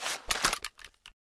ppsh41_holster.ogg